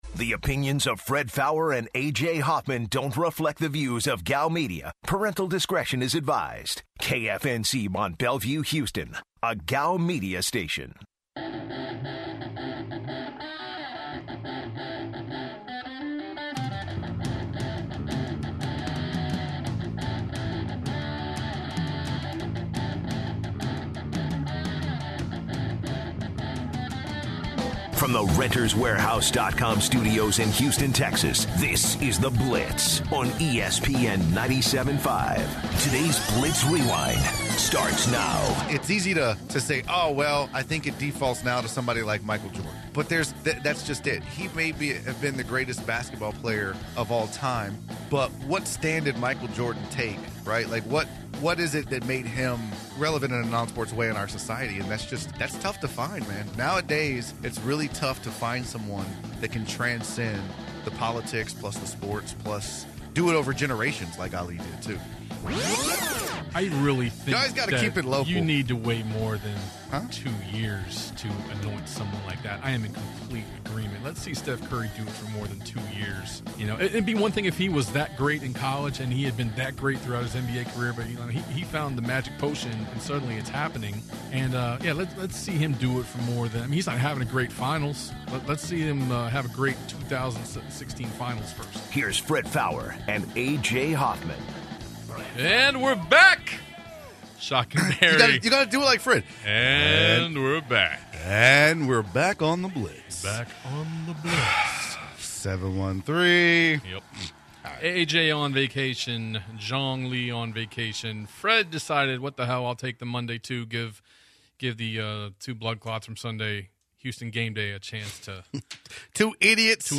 The last segment of The Blitz begins on a touching note when a listener calls in to share his battle with cancer